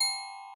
glock_G_4_2.ogg